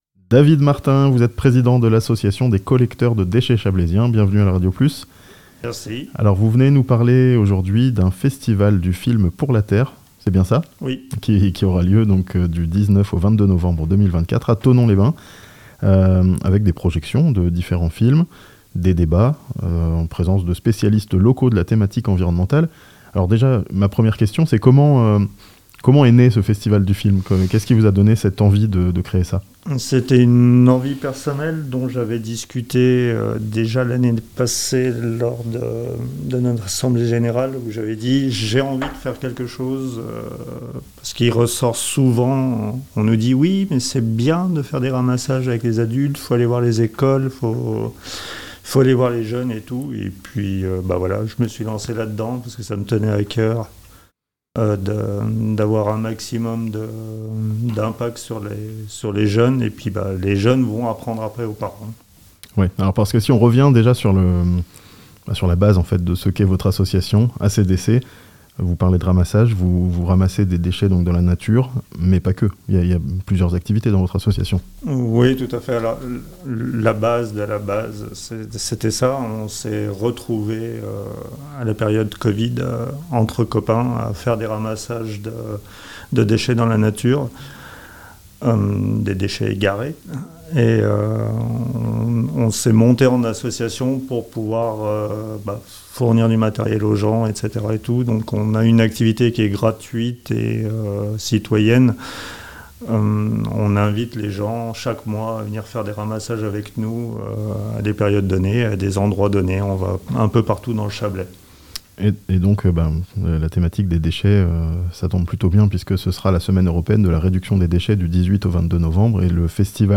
A Thonon, un festival du film pour la Terre (interview)